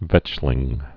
(vĕchlĭng)